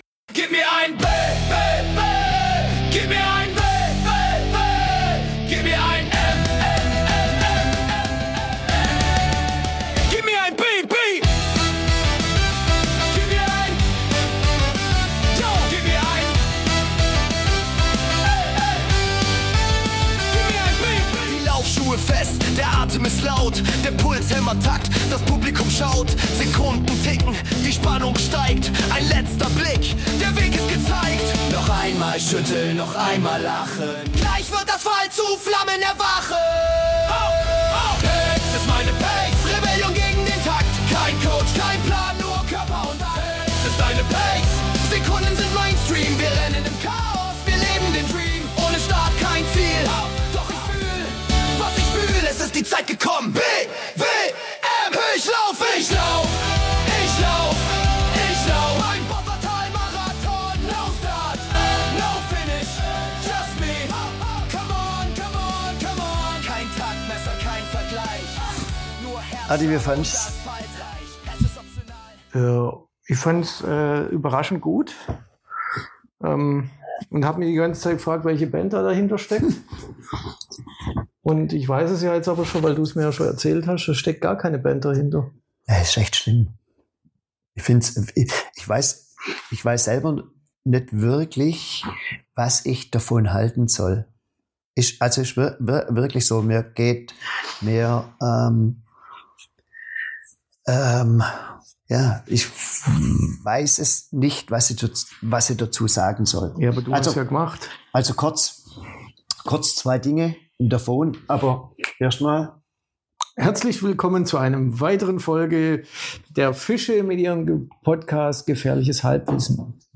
reden und einer macht Musik